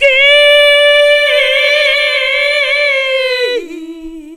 SCREAM 1.wav